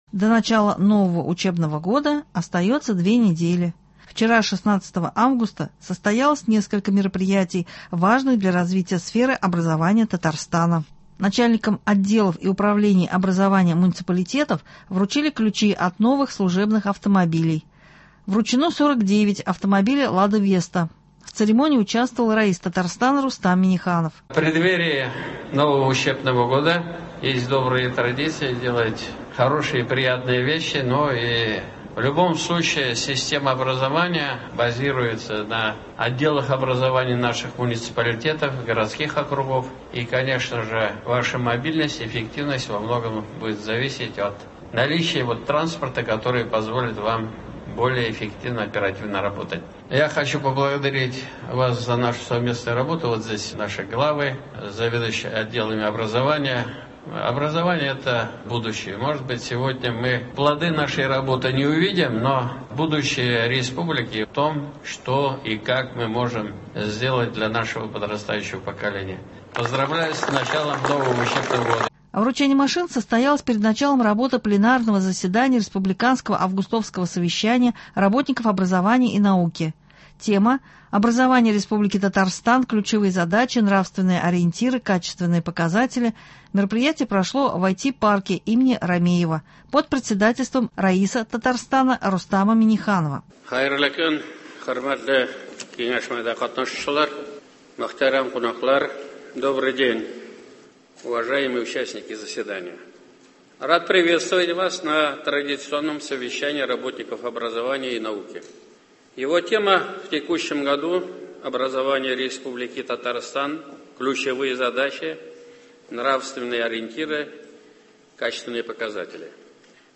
Репортаж с с пленарного заседания республиканского августовского совещания работников образования и науки Татарстана, прошедшего 16 августа.